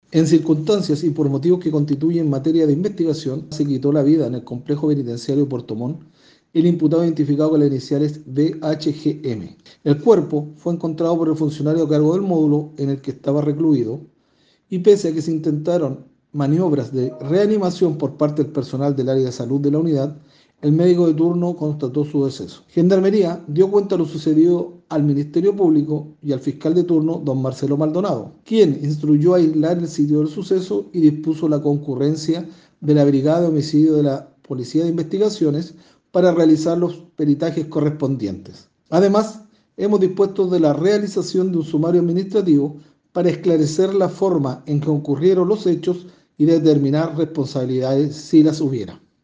Al respecto, entregó otros pormenores el director regional (S) de Gendarmería en Los Lagos, teniente coronel Ervin Wanderleben.